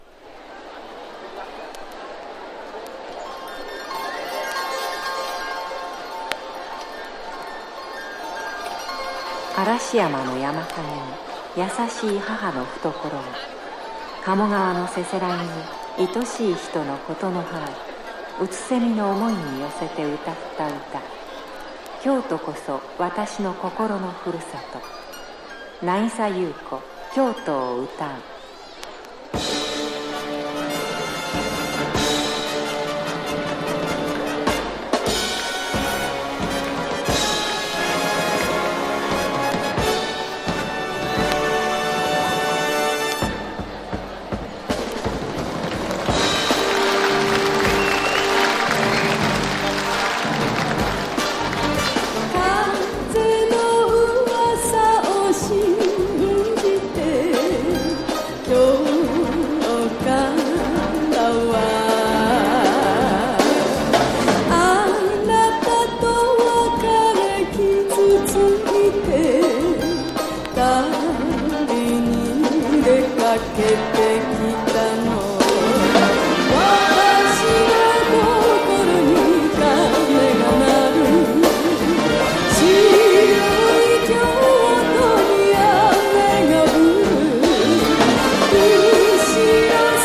'71年9月2日神戸国際ホールにおける実況録音盤。
和モノ / ポピュラー